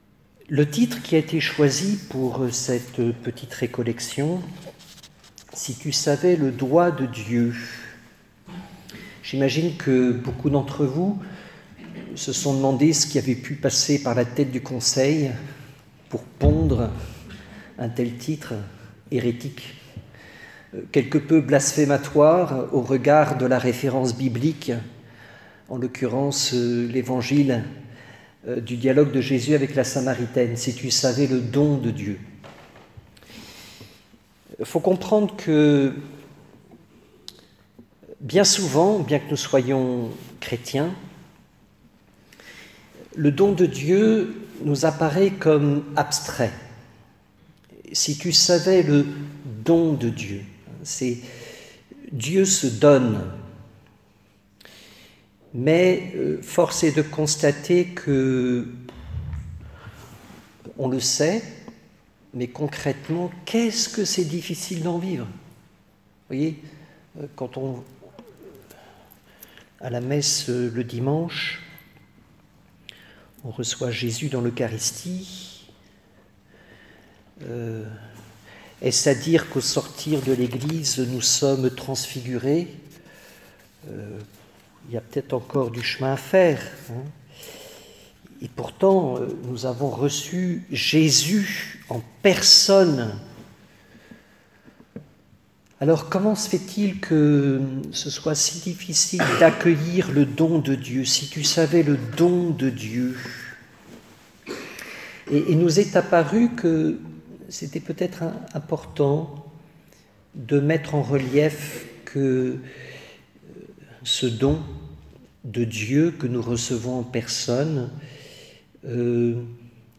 Vous trouverez ci-dessous la conférence : – Feuille de route : Cliquez ici – En écoute sur le site : – En téléchargement : Cliquez ici (clic droit, enregistrer sous)